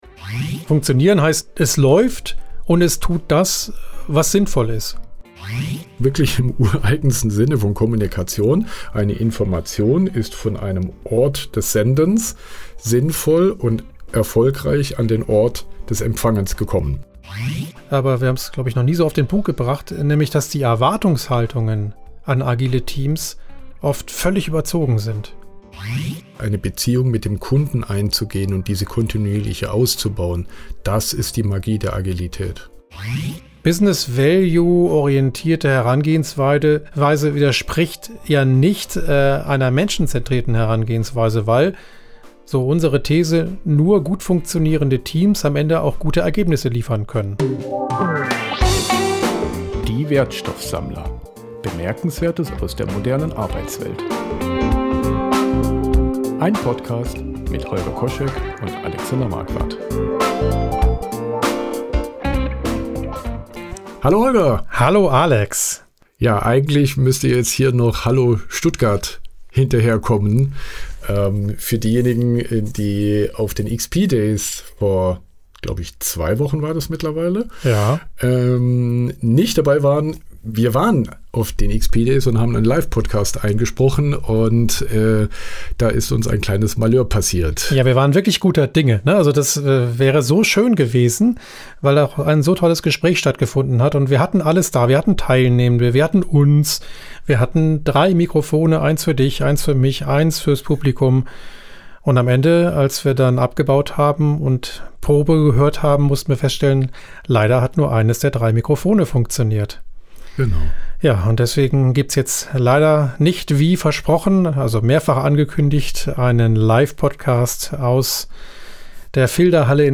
Offene Konferenz für Agile Software Entwicklung und Extreme Programming mit Fokus auf Softwareentwicklungsthemen.